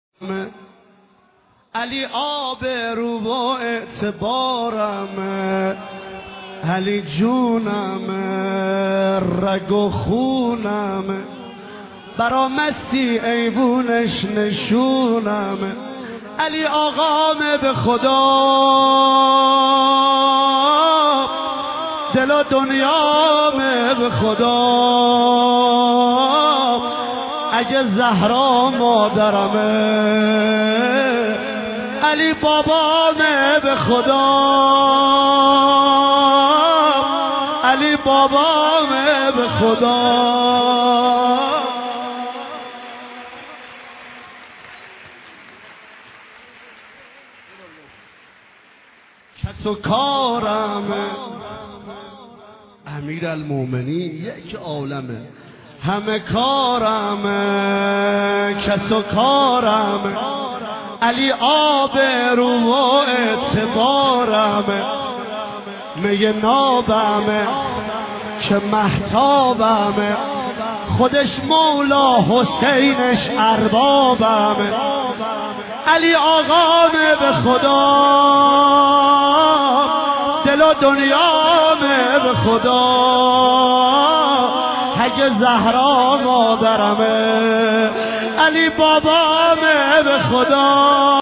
شعر خوانی
به مناسبت عیدغدیر